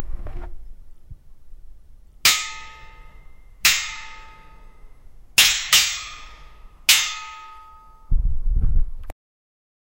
the sound of two metal man fighting, like a boxing match
the-sound-of-two-metal-luyvllfp.wav